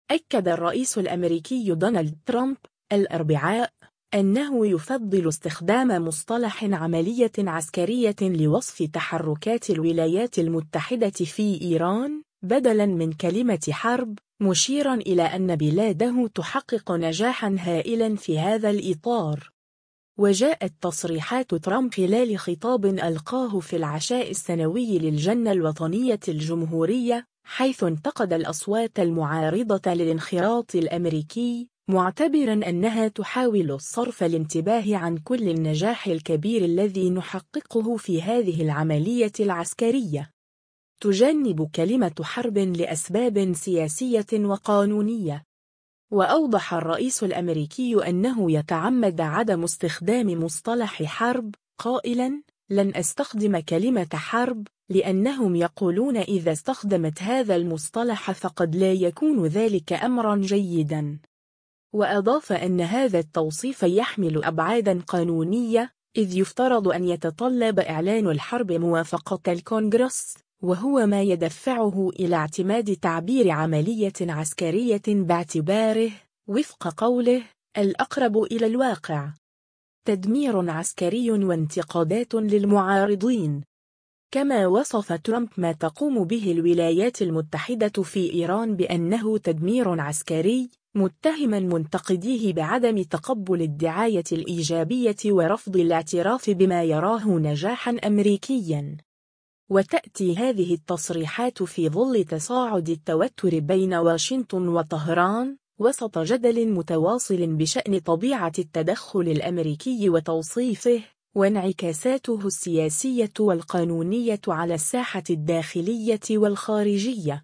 وجاءت تصريحات ترامب خلال خطاب ألقاه في العشاء السنوي للجنة الوطنية الجمهورية، حيث انتقد الأصوات المعارضة للانخراط الأمريكي، معتبرا أنّها تحاول “صرف الانتباه عن كل النجاح الكبير الذي نحققه في هذه العملية العسكرية”.